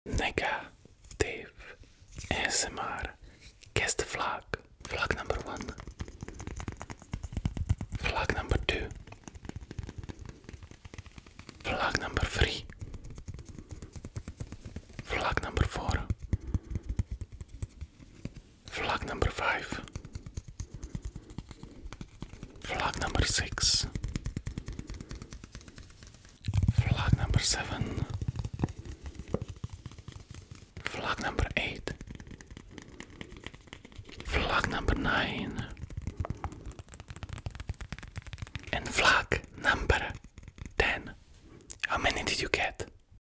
ASMR | Guess the Flag sound effects free download